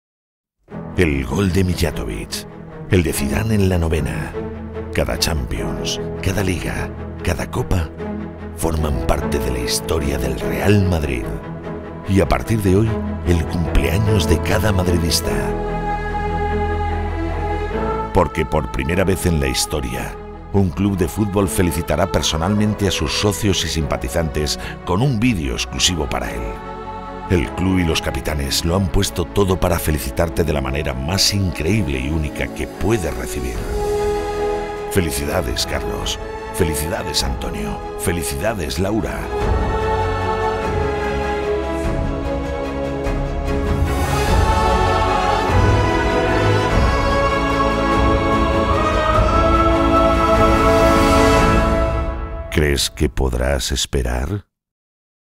Sprechprobe: Sonstiges (Muttersprache):
SPOT TV REAL MADRID.mp3